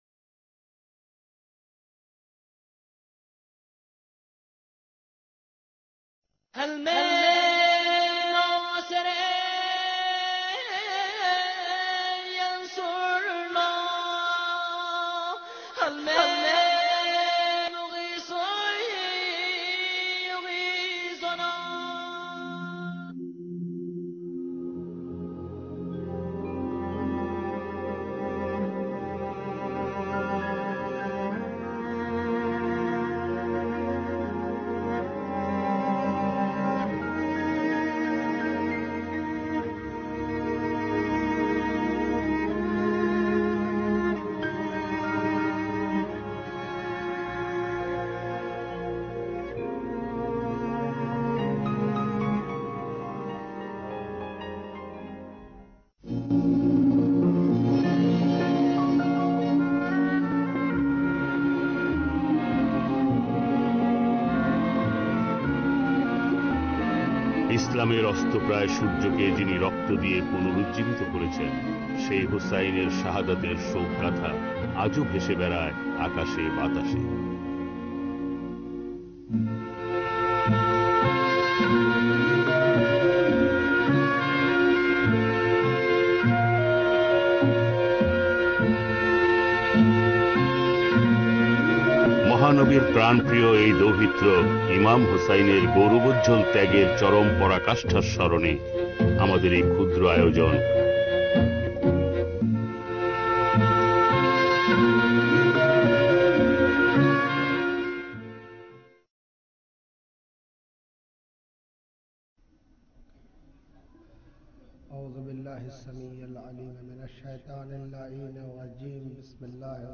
ইমম-হসইনর-আ-শক-গথ